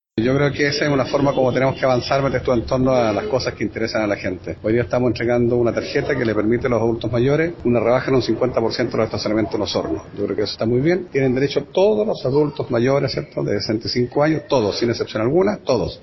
Este beneficio –relativo a la entrega de la respectiva credencial- favorece a adultos mayores desde 65 años que hayan obtenido tanto su licencia de conducir como permiso de circulación en Osorno, siendo una medida pionera en la región dijo el alcalde, Jaime Bertín.